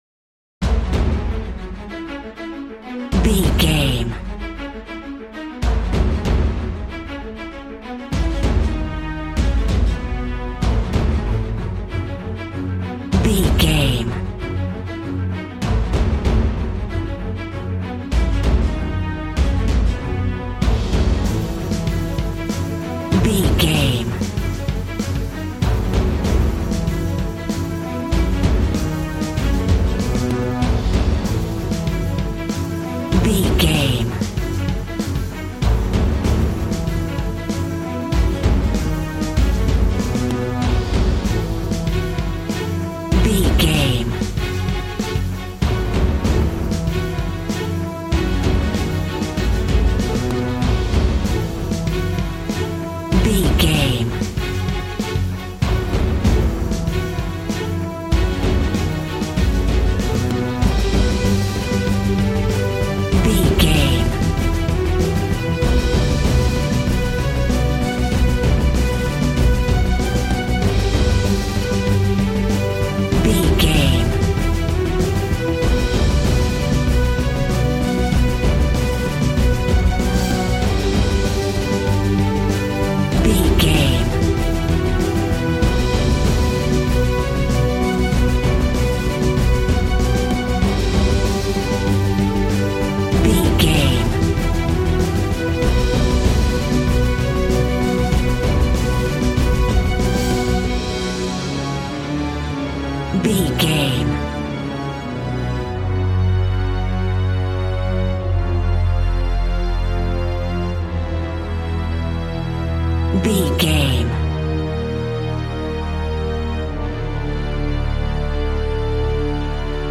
Epic / Action
Fast paced
In-crescendo
Aeolian/Minor
strings
brass
percussion
synthesiser